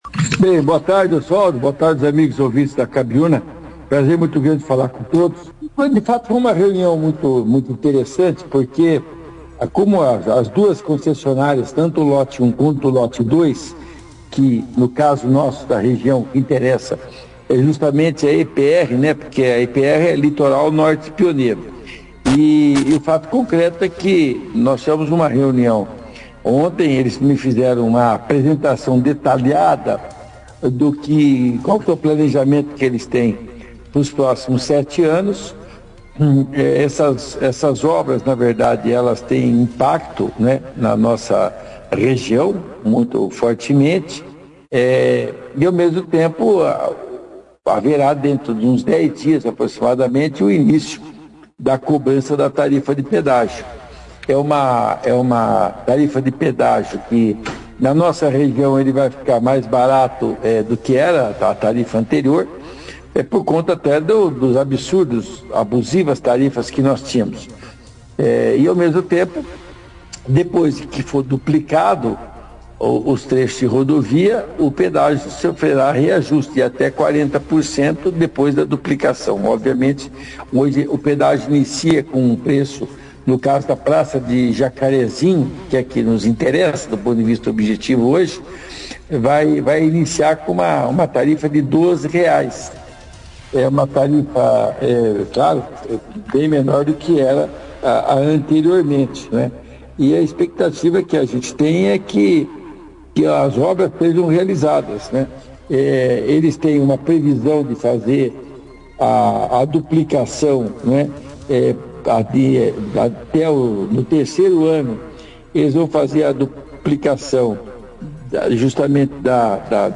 Após Reunião com Concessionária, Deputado Romanelli Detalha Prazo para Duplicação da BR-369 em Entrevista ao Operação Cidade - Rádio Cabiuna
O deputado Luiz Claudio Romanelli participou, nesta quarta-feira, 13 de março, da 2ª edição do jornal “Operação Cidade”, trazendo detalhes de uma reunião com dois diretores da concessionária EPR Litoral Pioneiro, responsável pelo Lote 2 de concessões de estradas que abrangem nossa região.
Durante a entrevista, Romanelli expôs informações importantes para os usuários da BR-369, da região, incluindo o preço de R$12,00 a ser cobrado na praça de pedágio de Jacarezinho, na divisa com o estado de São Paulo. Também destacou o prazo para a duplicação da BR-369, começando a partir da pista dupla em Cornélio Procópio e terminando no trecho duplicado da rodovia próximo ao entroncamento com a BR-153 (Rodovia Transbrasiliana) em Jacarezinho, antes da divisa com São Paulo.